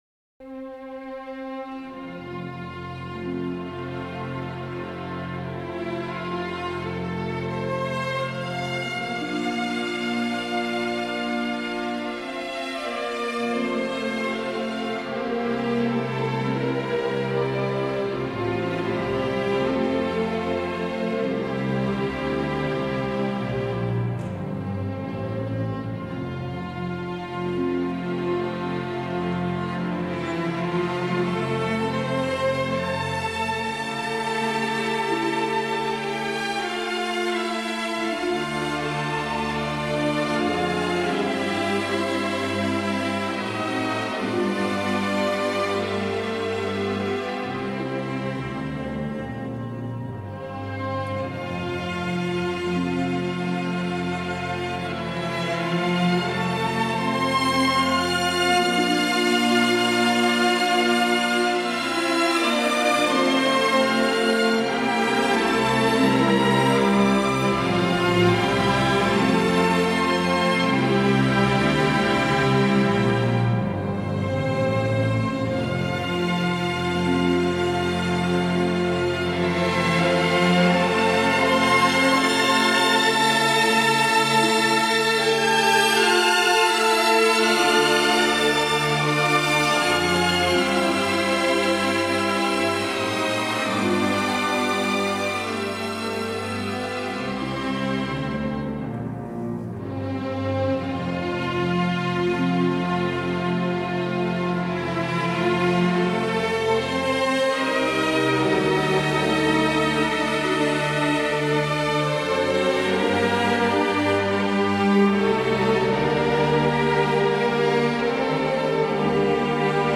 碟中所辑曲目包括小提琴、钢琴、竖琴。
钢琴敲击时的重量感、小提琴迫近时的清晰细节，让这张专辑变得更加畅销了。
一听后就忘不了，透明、清甜、高雅、细致，又有厚度和穿透力，放起来满堂生辉。"